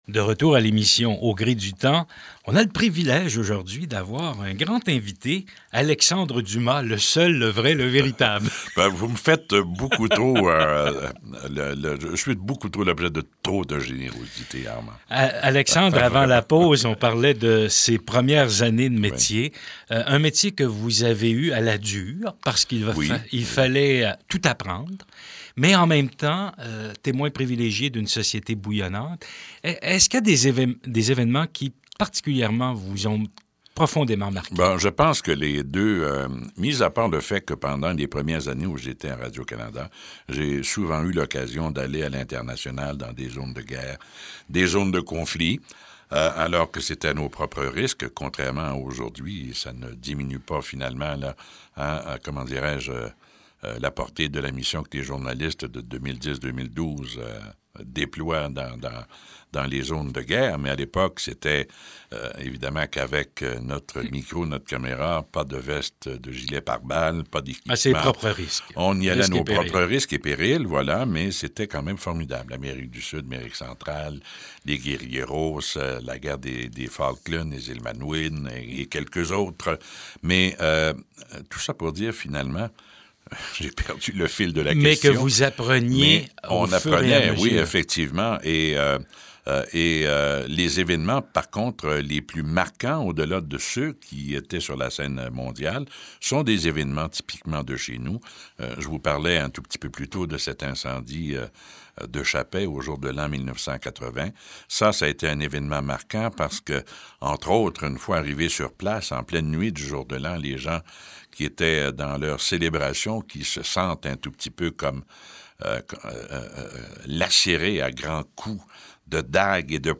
Entrevue radio